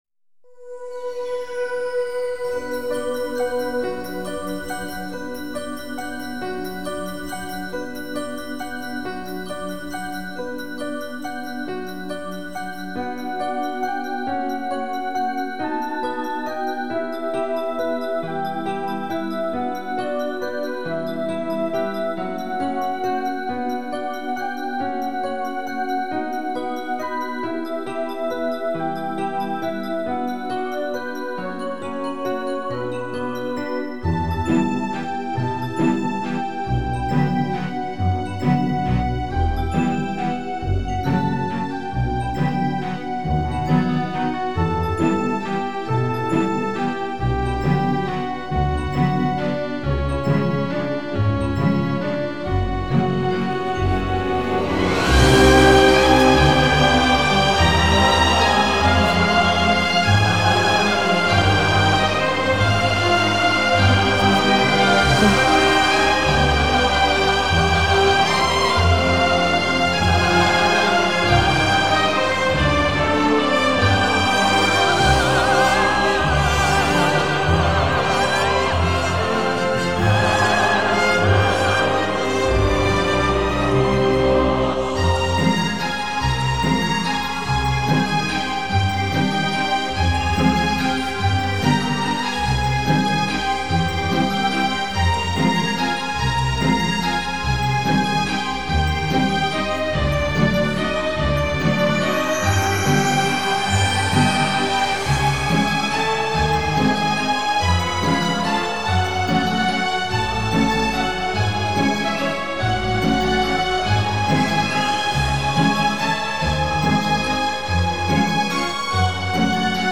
Минусовки